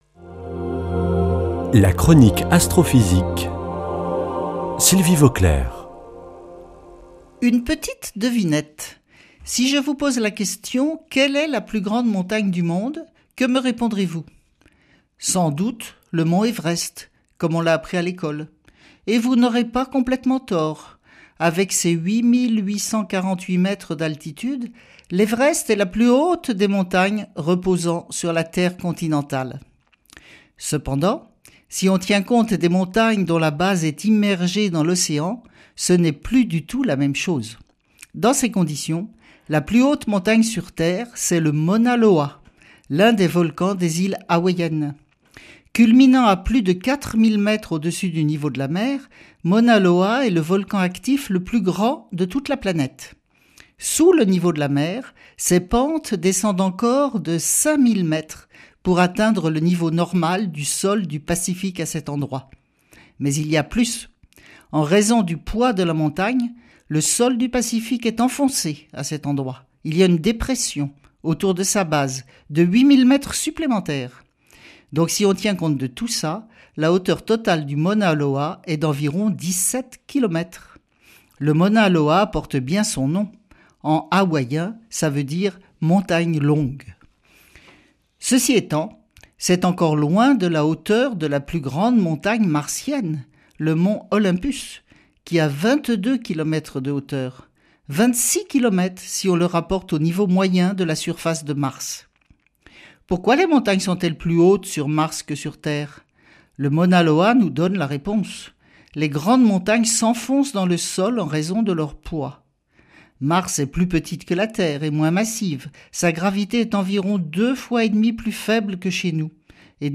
Astrophysicienne